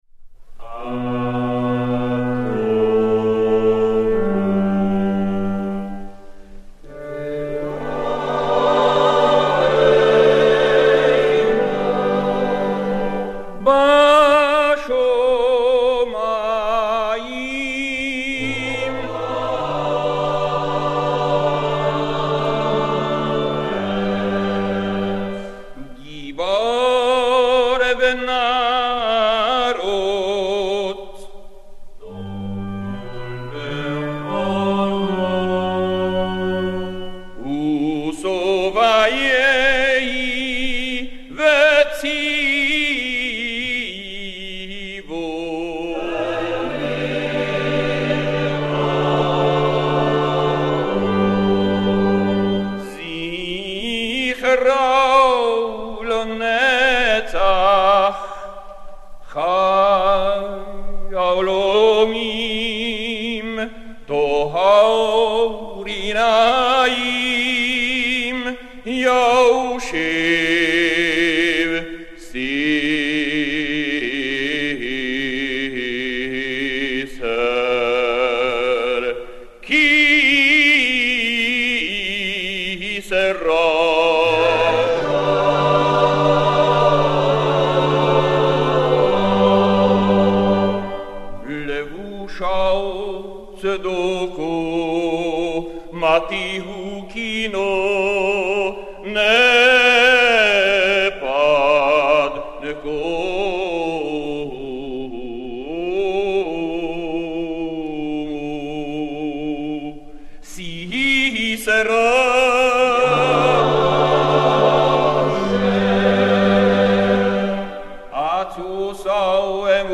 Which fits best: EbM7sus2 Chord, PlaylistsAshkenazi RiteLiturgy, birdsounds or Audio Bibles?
PlaylistsAshkenazi RiteLiturgy